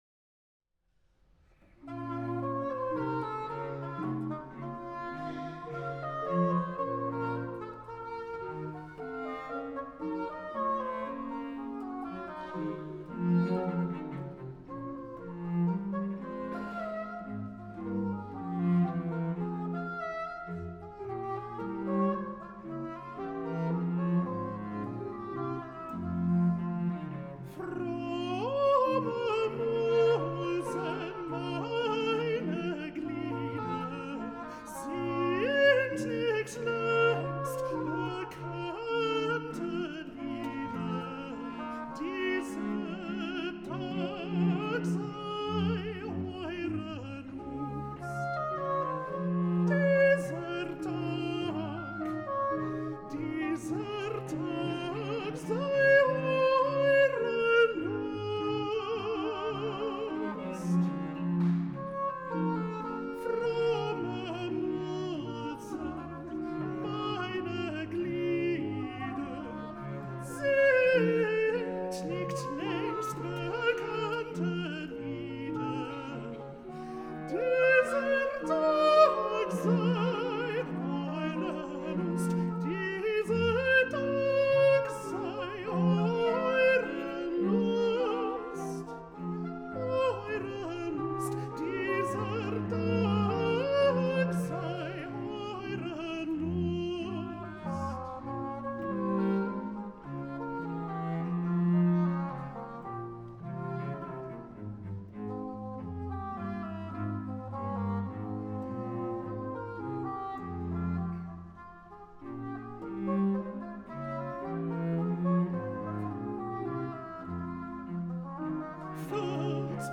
Counter-tenor
J.S. BACH ALTO ARIA ‘FROMME MUSEN! MEINE GLIEDER!’ from SECULAR CANTATA ‘Tönet, ihr Pauken! Erschallet, Trompeten!’ BWV 214. Live performance Temple Church, London
Oboe d’amore
cello
chamber organ